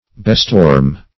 Bestorm \Be*storm"\